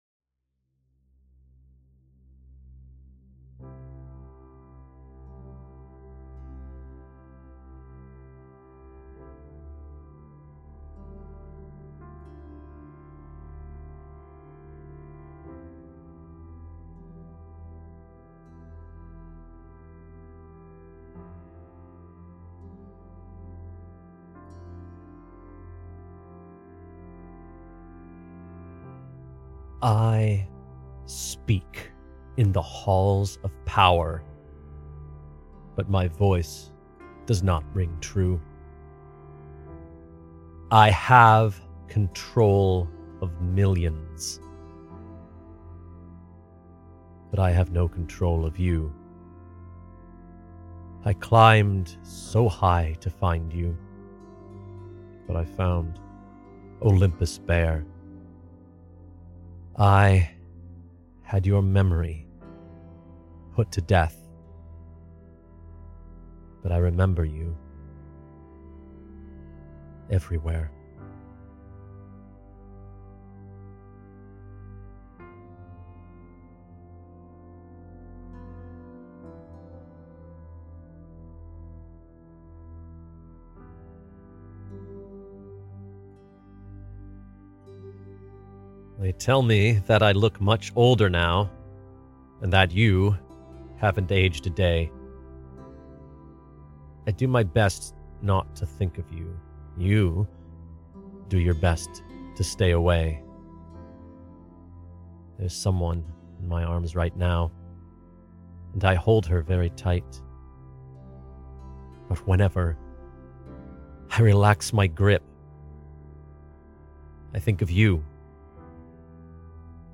I'm still having some issues with sound qua